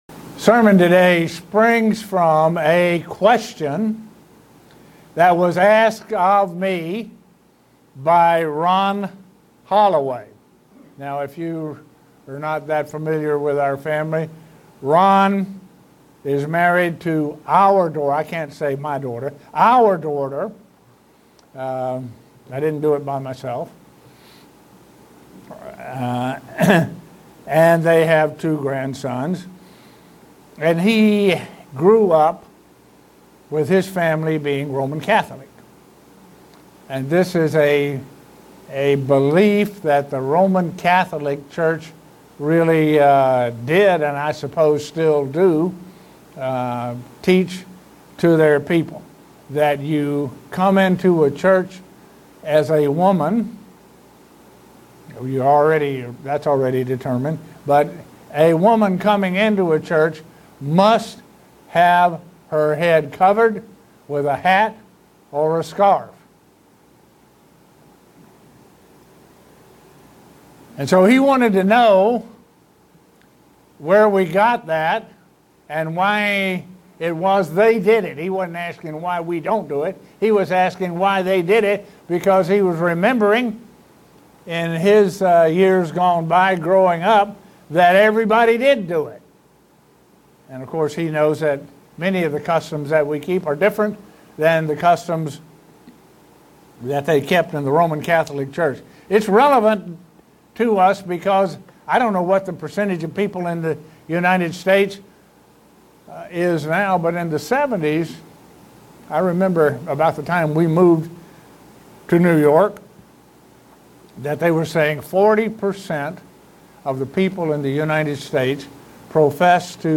Given in Buffalo, NY
Print Bible instruction regarding head covering and how it is misunderstood. sermon Studying the bible?